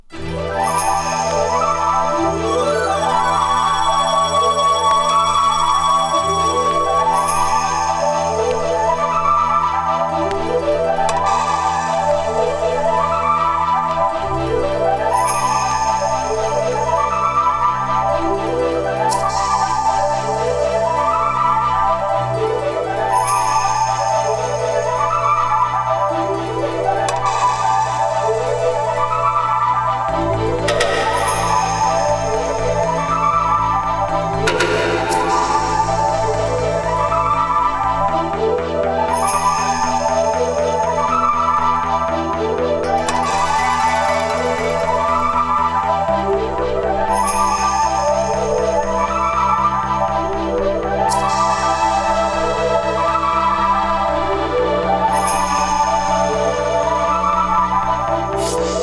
今こそ聴きたいエスノ・ニューエイジ・サウンド